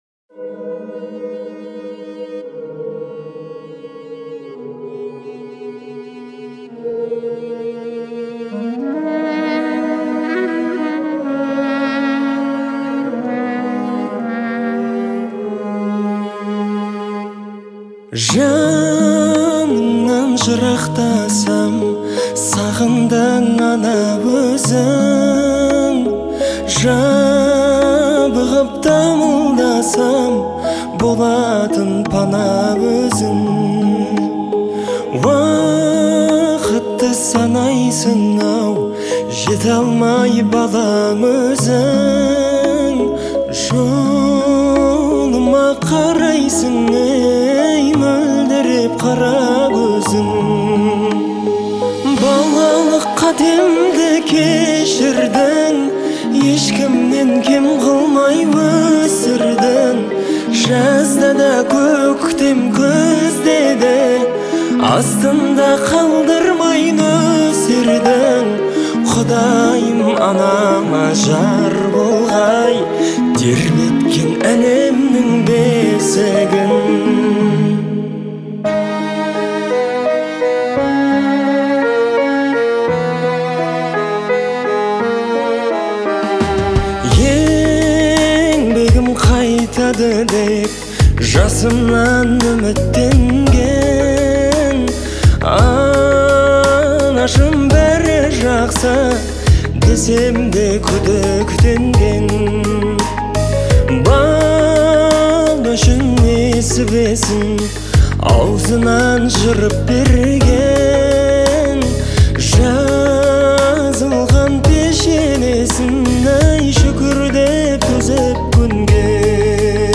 это трогательная балада в жанре казахской народной музыки.